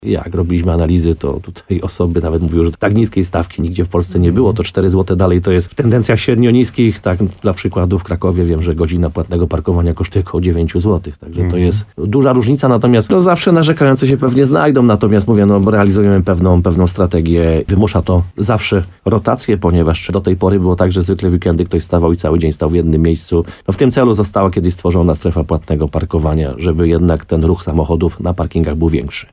Jak uzasadniał w programie Słowo za Słowo w RDN Nowy Sącz burmistrz Krynicy-Zdroju Piotr Ryba, wcześniejsze kwoty, jak i obecna podwyżka są niewielkie w porównaniu z większością miast w Polsce, a zmiany i tak były konieczne.